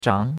zhang2.mp3